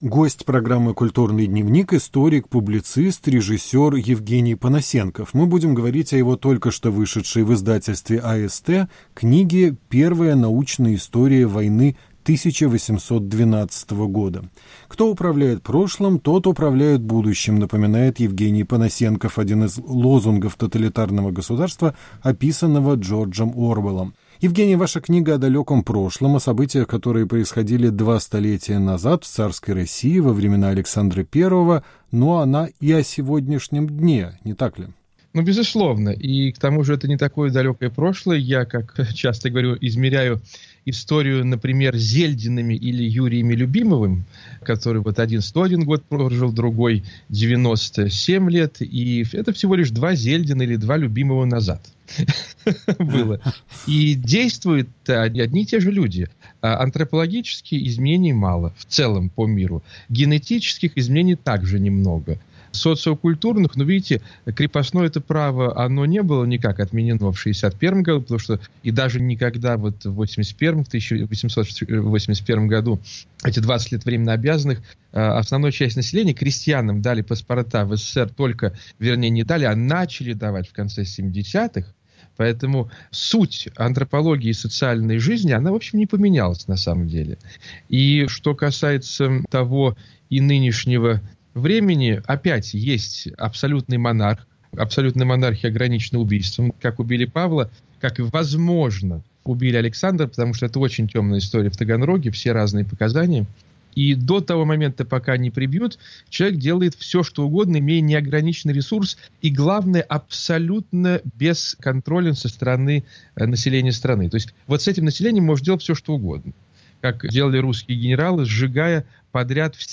Разговор с Евгением Понасенковым в программе "Культурный дневник"